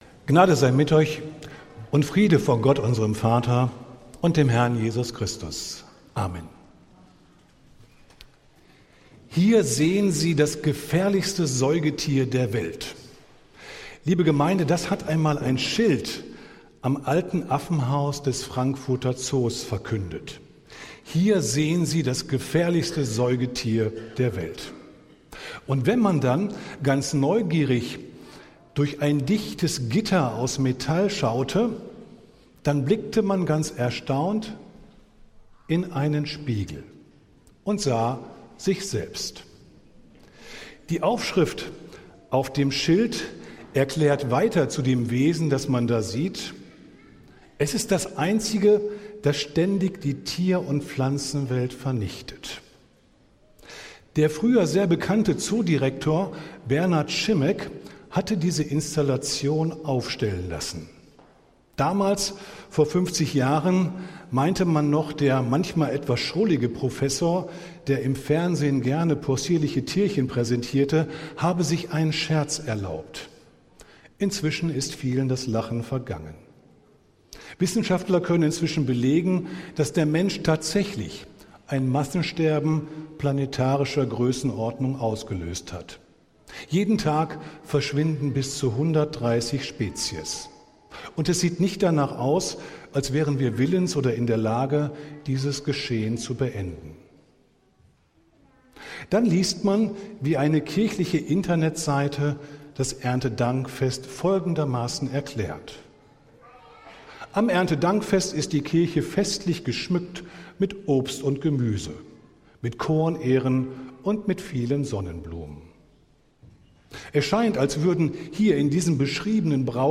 Predigt des Gottesdienstes aus der Zionskirche am Sonntag, den 5. Oktober 2025